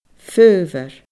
faobhar /fɯːvər/